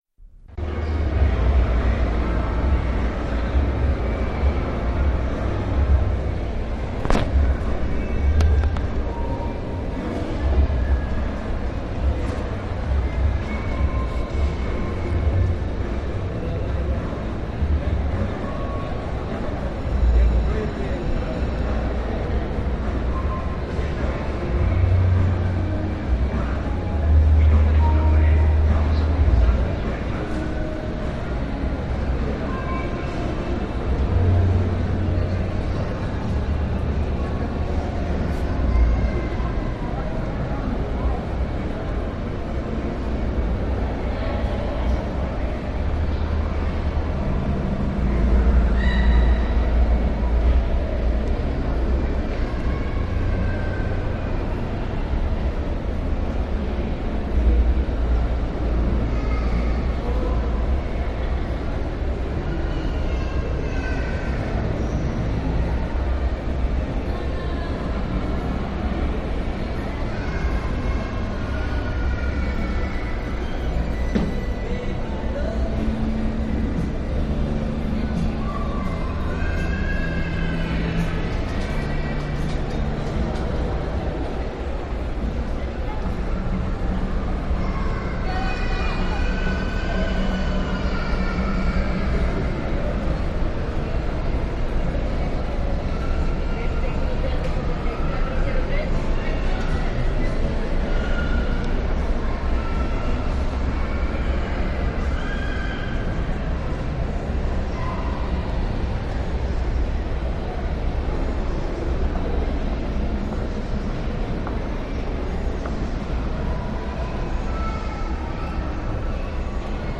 Paseo sonoro Plaza Boulevard
Esta plaza se encuentra ubicada al poniente de la ciudad de Tuxtla Gutierrez Chiapas, Mexico, a un costado tambien se encuentra la plaza Crystal.
El recorrido inicia en el segundo piso en la entrada de Liverpool, en todo el trayecto se puede oír el rumor del ambiente ese "hummmmmmmm" producido por la mezcla del eco que produce la pesima arquitectura sonora que tiene el inmueble que llega a niveles que molestan.
Esta plaza pues tiene una gran cantidad de ruido, basta decir que en el comedor donde se encuentran los restaurantes de comida rápida es casi imposible sostener una conversación a un volumen normal de la voz.